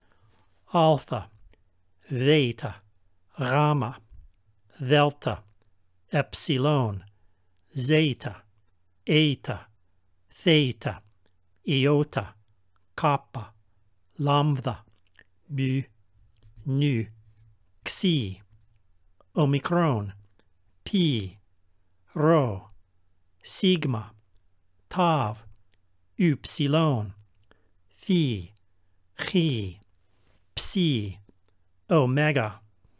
Alpha is a vowel and always sounds like the a in the English word spa.
Alpha always sounds like the a in spa.